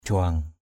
/ʥʊa:ŋ/ (đg.) đảo, lượn = planer. ahaok par juang a_h<K pR j&/ máy bay lượn = l’avion plane.